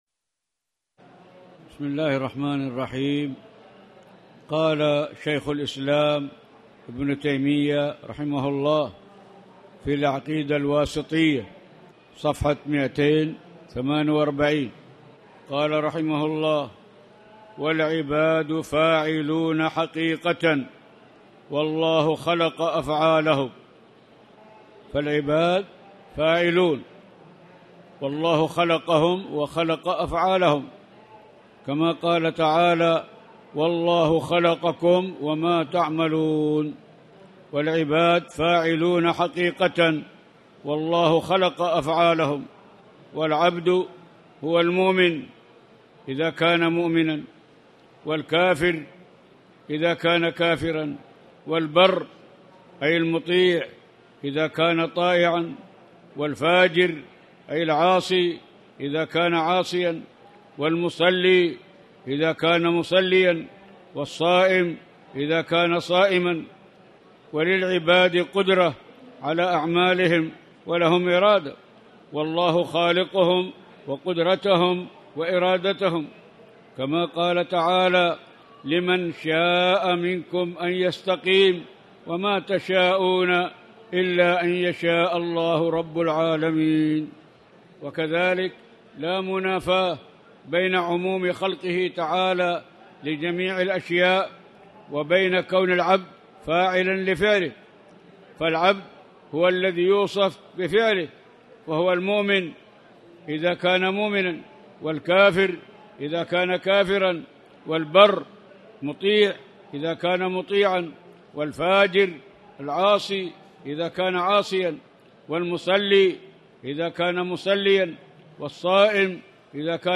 تاريخ النشر ١٤ ذو القعدة ١٤٣٨ هـ المكان: المسجد الحرام الشيخ